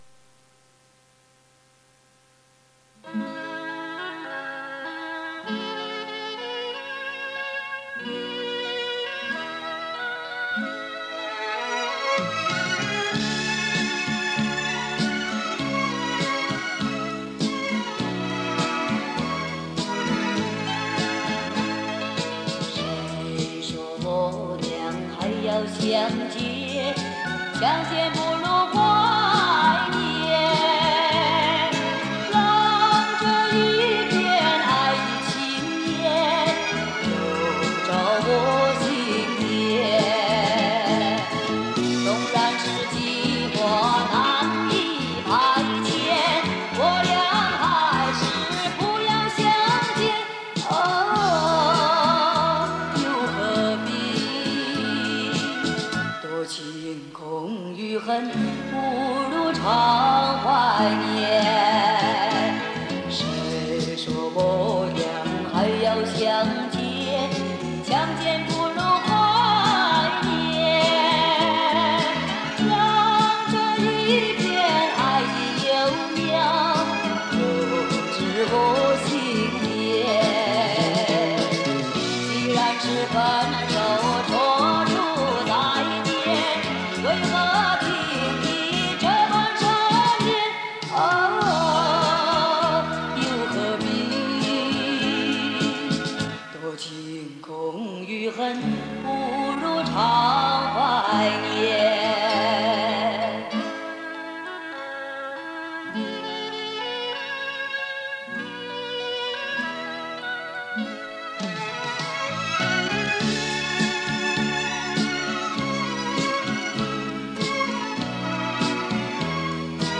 磁性的声音